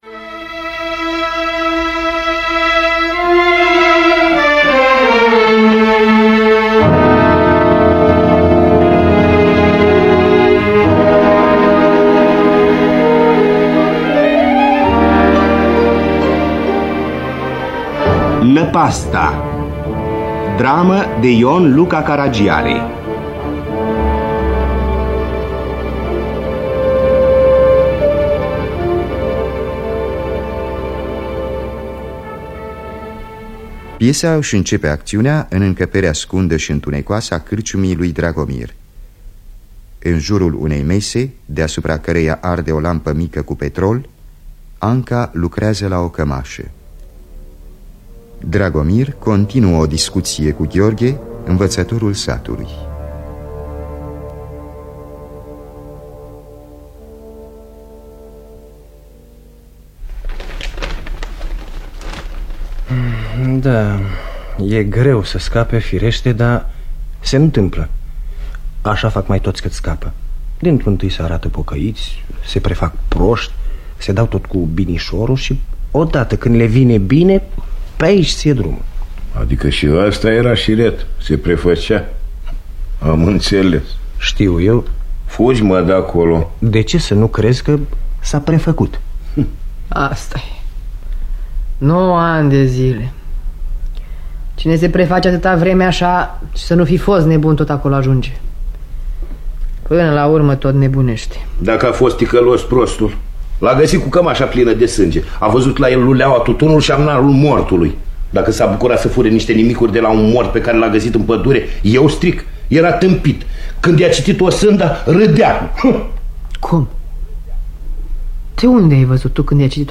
Teatru Radiofonic Online
Adaptarea radiofonică